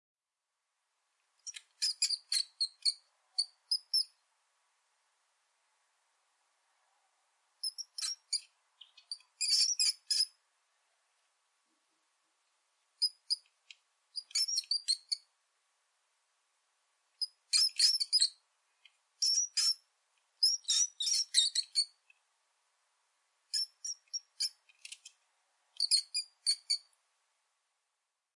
Mouse Squeaks Efecto de Sonido Descargar
Mouse Squeaks Botón de Sonido
Animal Sounds Soundboard143 views